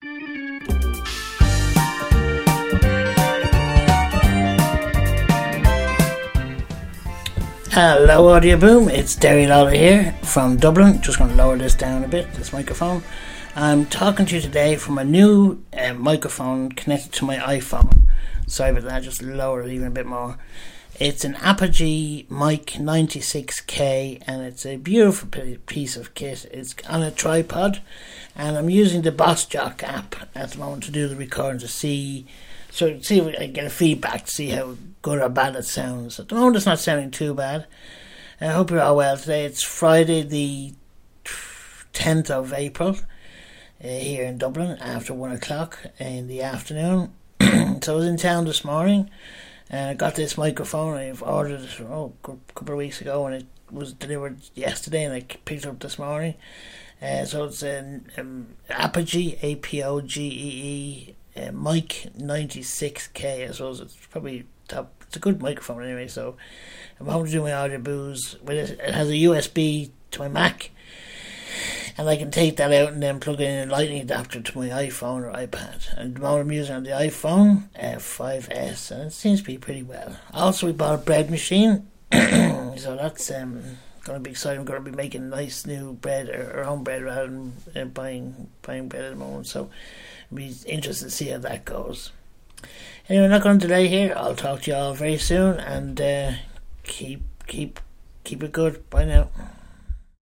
new mic test